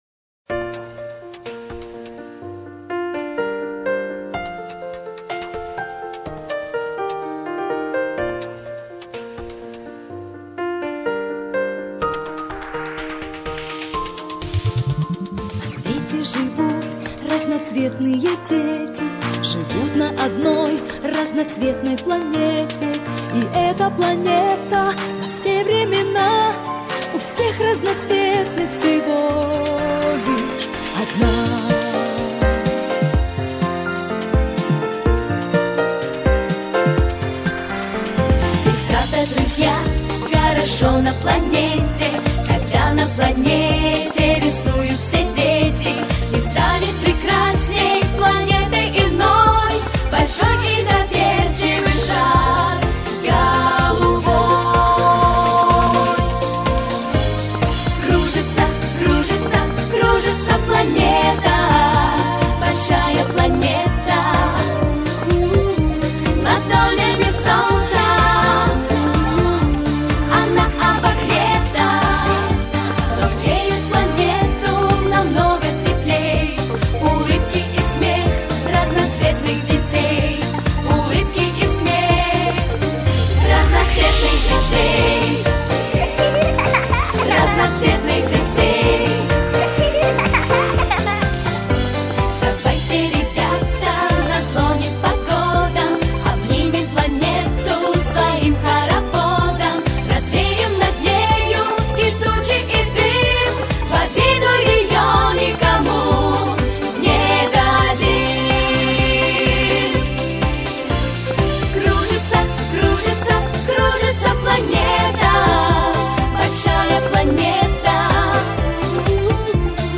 Детские песни и музыка